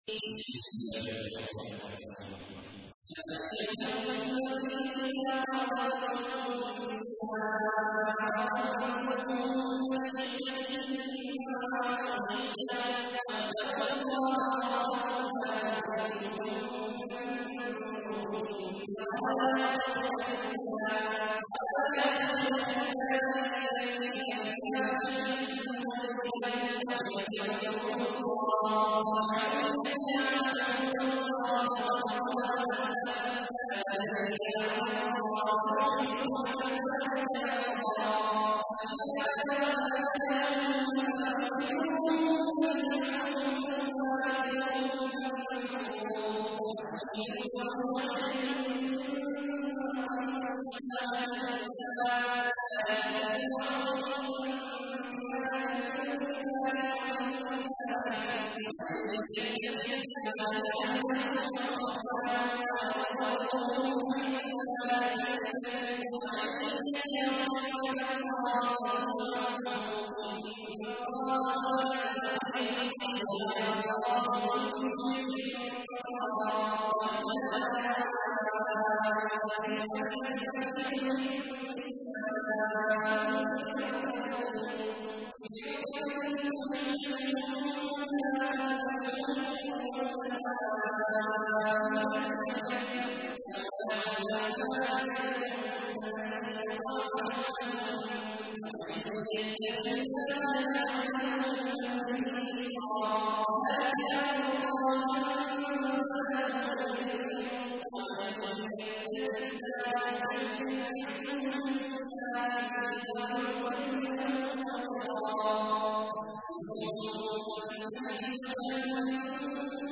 تحميل : 65. سورة الطلاق / القارئ عبد الله عواد الجهني / القرآن الكريم / موقع يا حسين